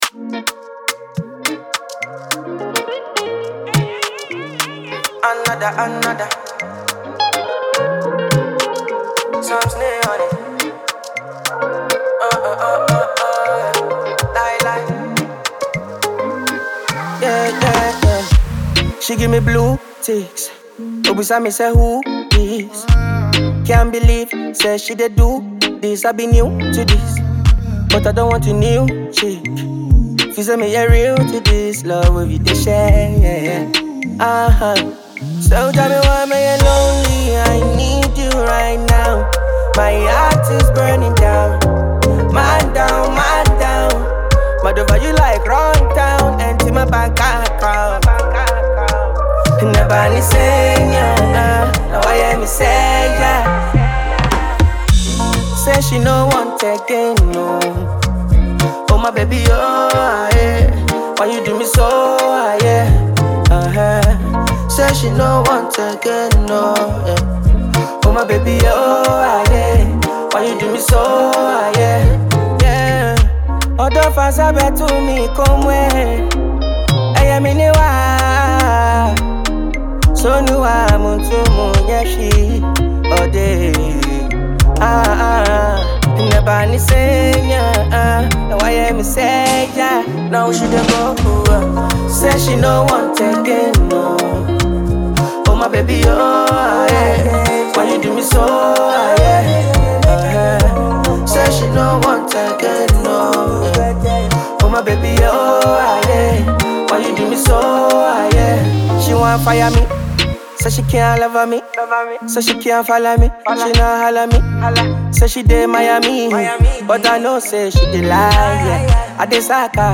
silky vocals
creating a song that is both relatable and radio-friendly.
or Ghana Afrobeats 2025 will enjoy this release.